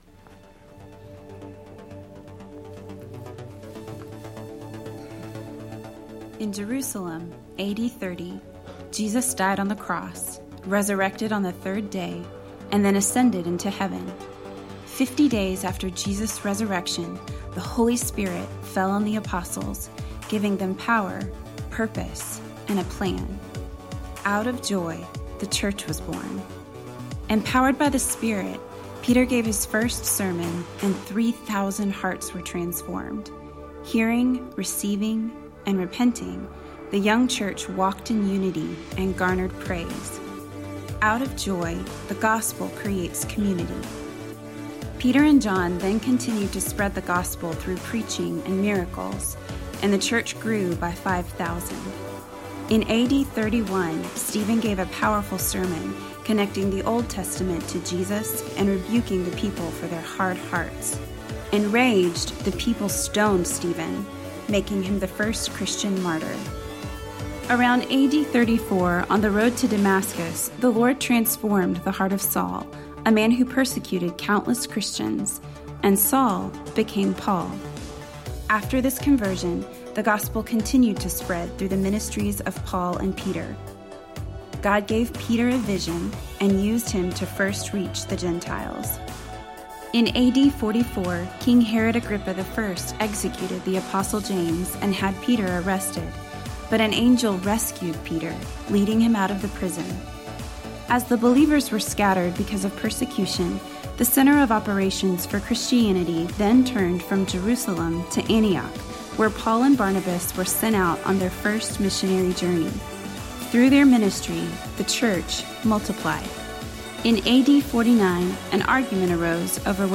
Location: High Plains Harvest Church Passage: Acts 20:1-16